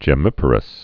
(jĕ-mĭpər-əs)